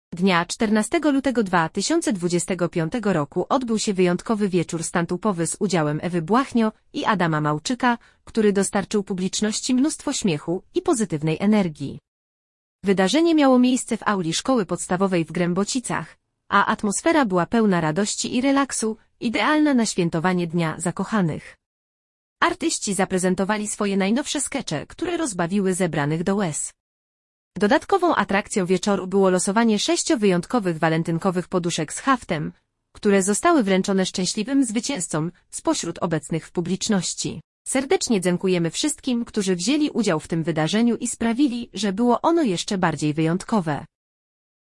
Wydarzenie miało miejsce w Auli Szkoły Podstawowej w Grębocicach, a atmosfera była pełna radości i relaksu, idealna na świętowanie Dnia Zakochanych. Artyści zaprezentowali swoje najnowsze skecze, które rozbawiły zebranych do łez.